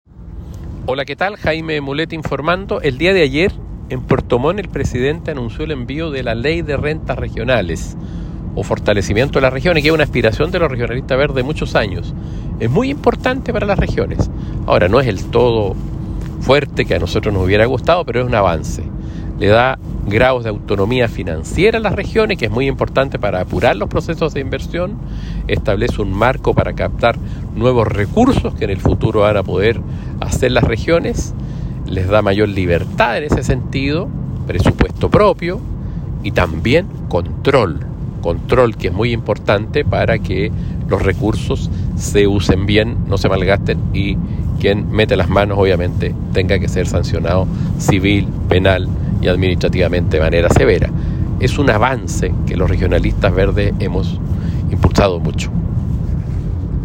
Audio del Diputado Jaime Mulet sobre la importancia de la Ley de Rentas Regionales.